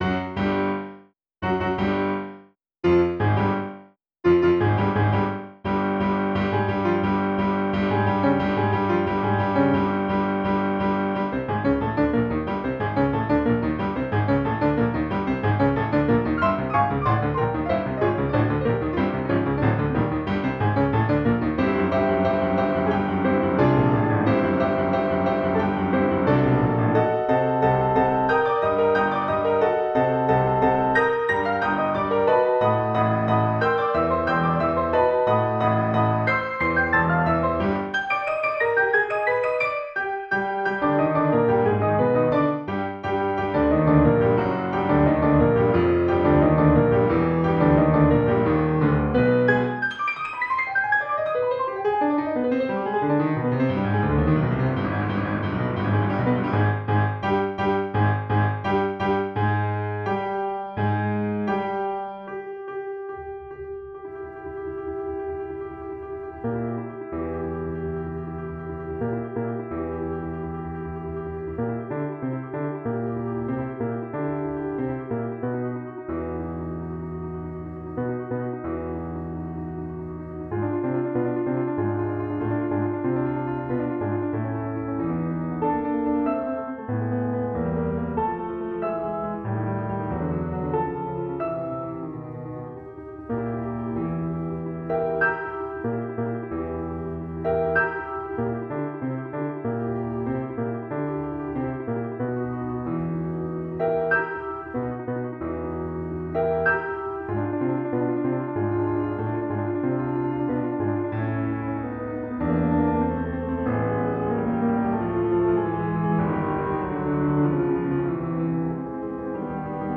This one is very simple and unprocessed. The only instrument is a piano (the Post Musical Instruments Bosendorfer 290, if you are interested). The mixdown file is normalized, but nothing else.